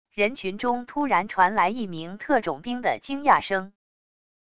人群中突然传来一名特种兵的惊讶声wav音频